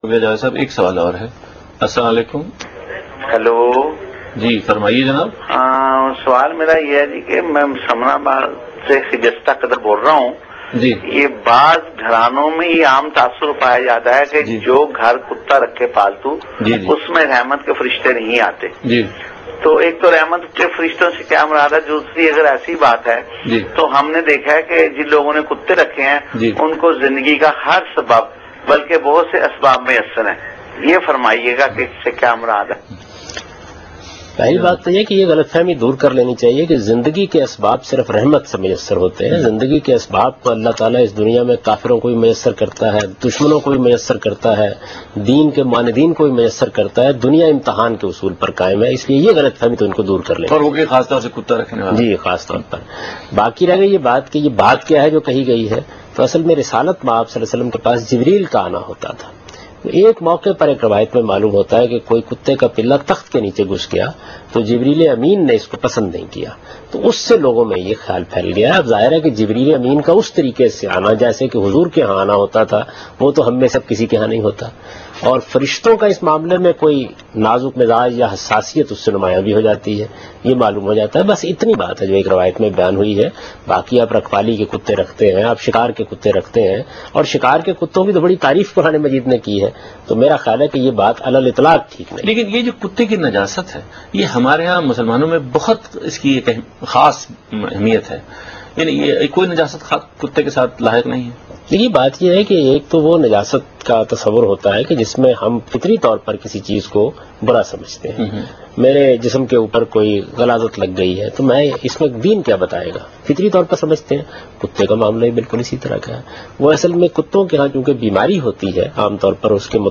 اس پروگرام میں جاوید احمد غامدی گھر میں کتا رکھنے کے مسئلہ پر گفتگو کر رہے ہیں